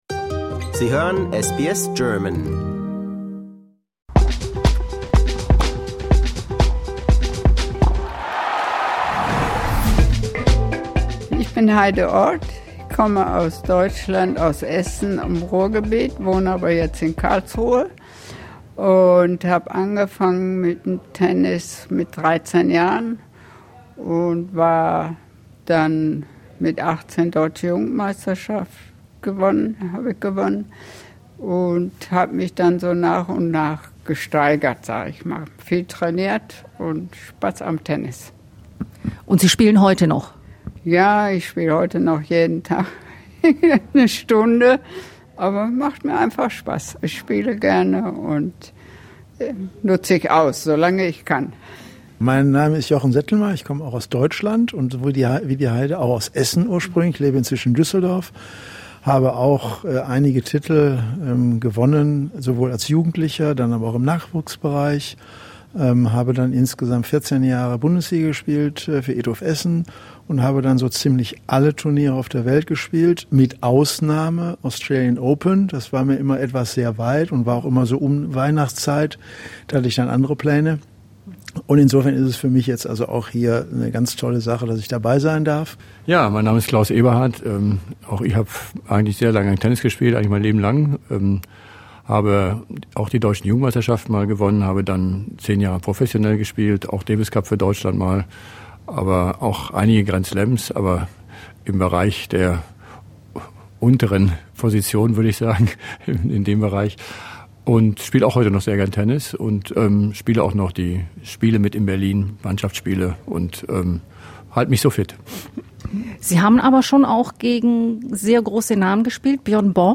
At the traditional Kooyong Tennis Club, the International Club of Australia's Grand Slam Reunion is currently taking place away from Melbourne Park.
This is where we met three former German tennis professionals and talked to them about the major changes in recent decades. We also talked about money and who this year's favorites for the Australian Open are.